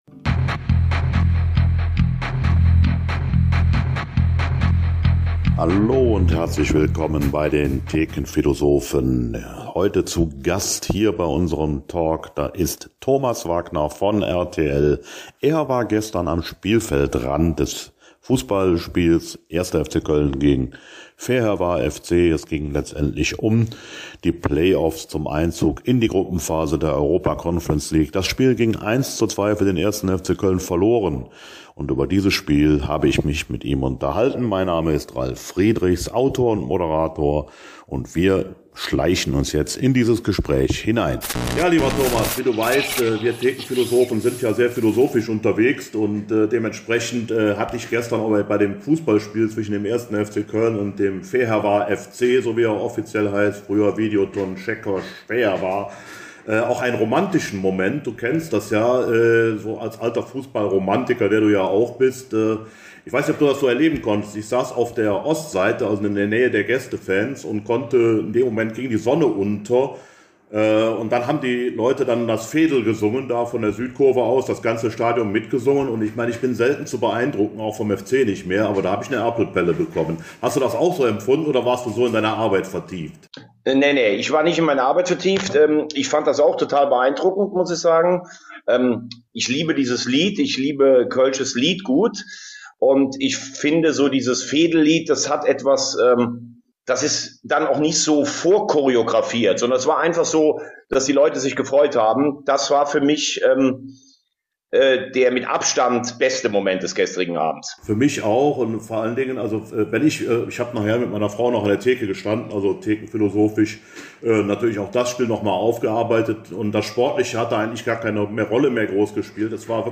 Beide tauschen im Kurzformat erste Erkenntnisse aus.